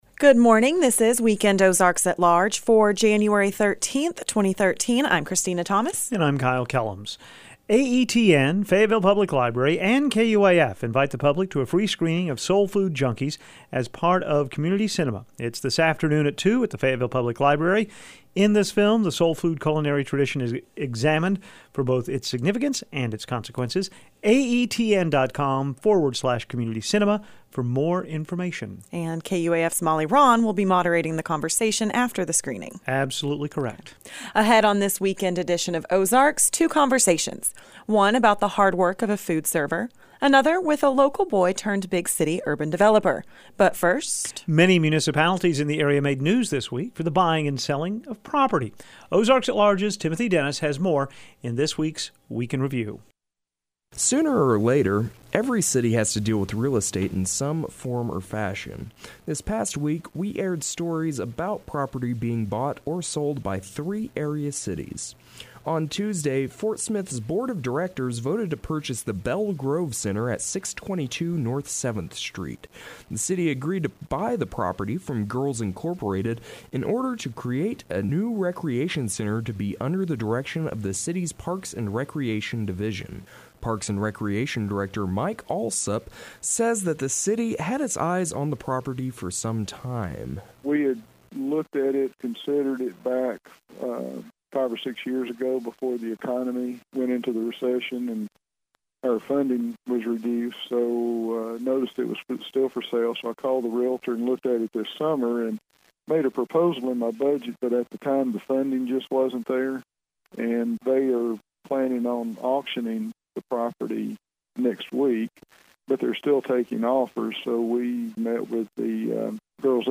On this weekend edition of Ozarks: two conversations. One about the hard work of a food server, another with a local boy turned big city developer.